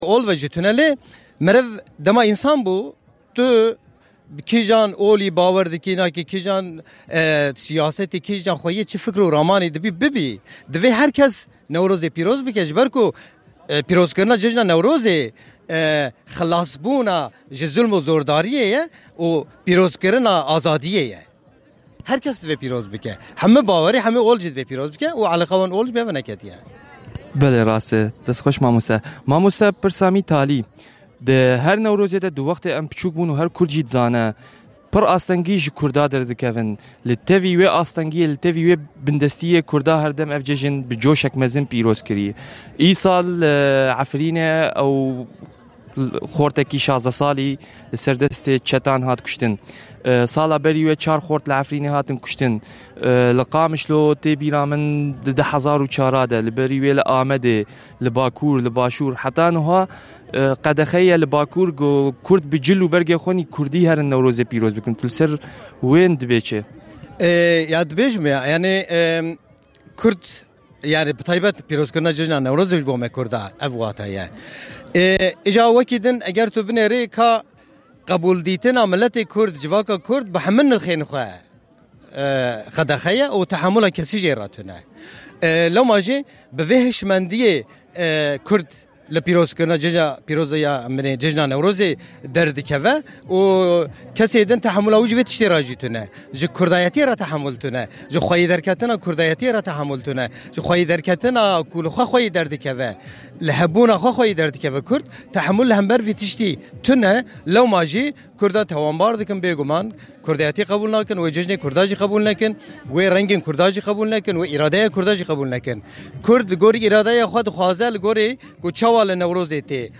Seit 2016 gibt es bei unserem Schwesternradio Radio Corax die mehrsprachige Sendung Common Voices. Es ist eine Sendung von Gefl�chteten und MigrantInnen in Halle und Umgebung.